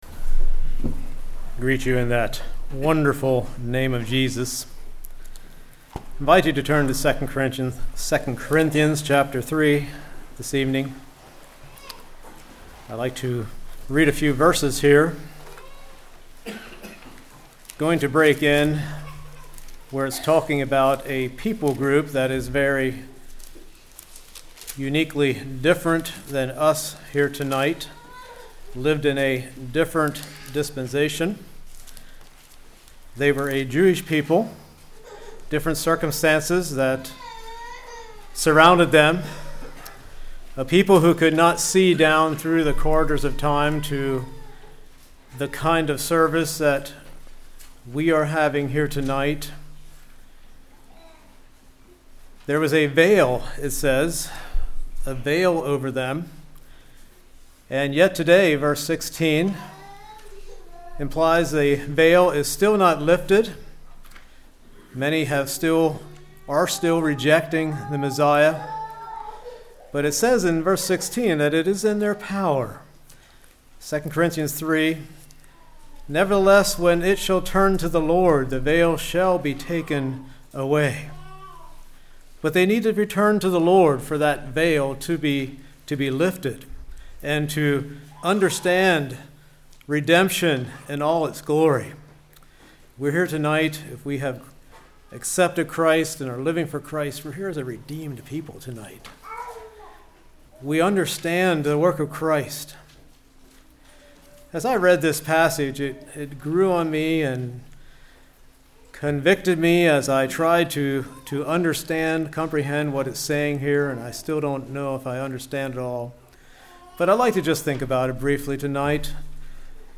Play Now Download to Device With Open Face Congregation: Elm Street Speaker